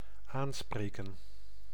Ääntäminen
IPA: [si.te]